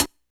Closed Hats
Hat (51).wav